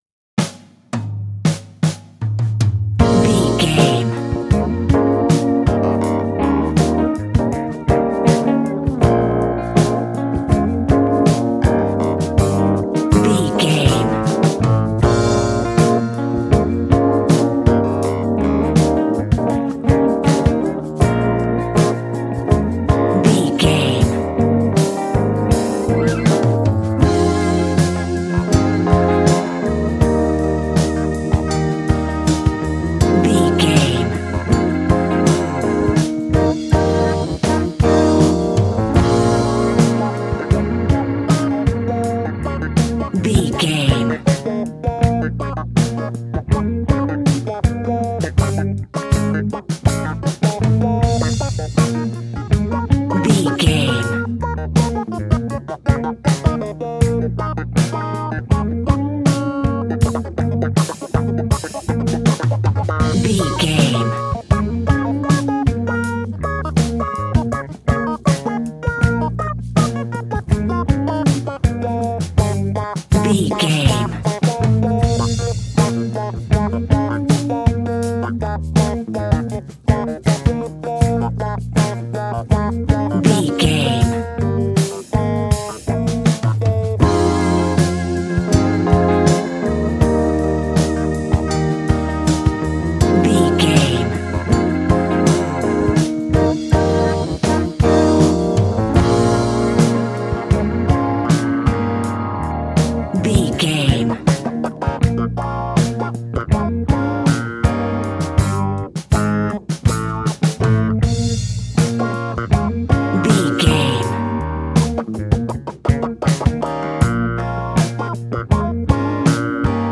Aeolian/Minor
B♭
funky
uplifting
bass guitar
electric guitar
organ
drums
saxophone